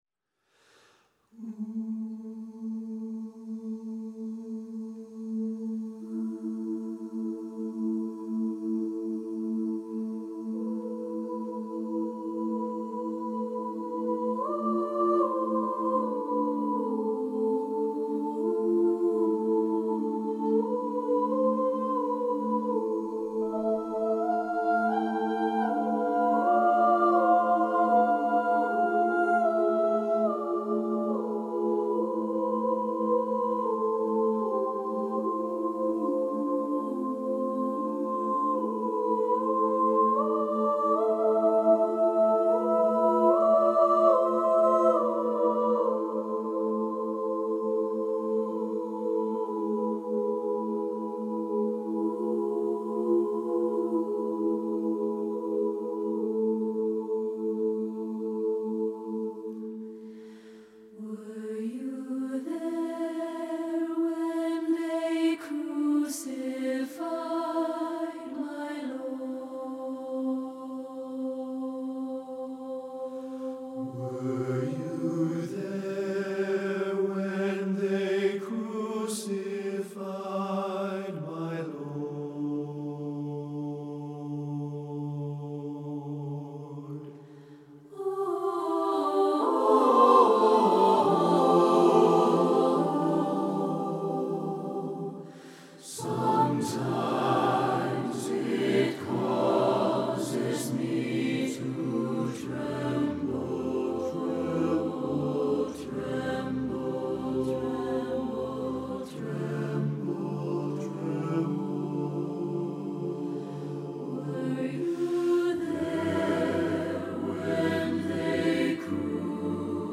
Voicing: SSATB a cappella